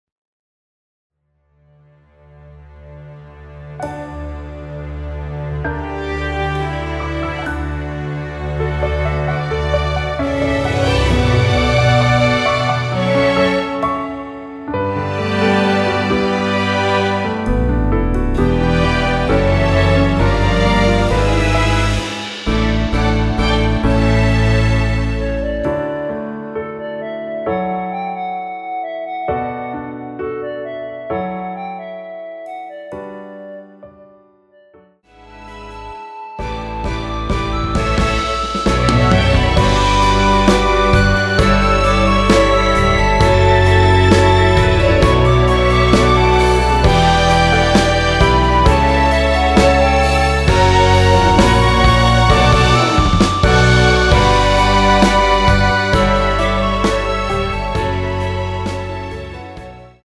원키에서(+5)올린 멜로디 포함된 MR입니다.
앞부분30초, 뒷부분30초씩 편집해서 올려 드리고 있습니다.
중간에 음이 끈어지고 다시 나오는 이유는